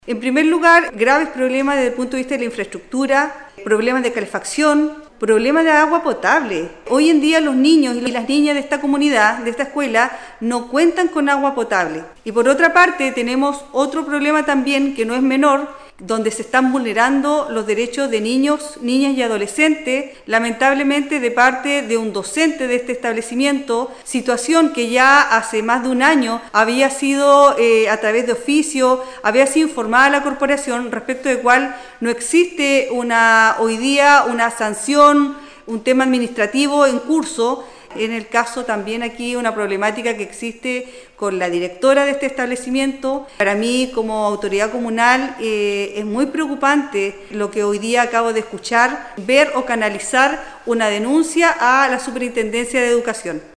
En tanto la Concejala de Castro, Yohana Morales, tras reunirse en el lugar con los apoderados, se refirió a los graves problemas que existen al interior de la escuela: